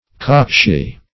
Cockshy \Cock"shy`\, n.